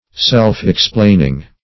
Meaning of self-explaining. self-explaining synonyms, pronunciation, spelling and more from Free Dictionary.